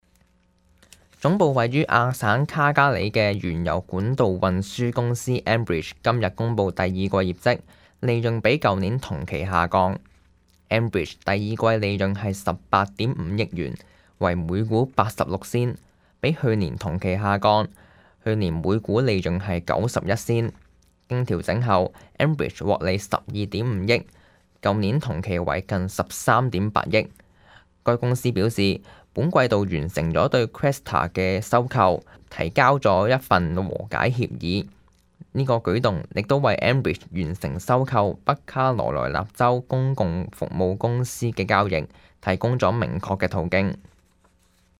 news_clip_20010.mp3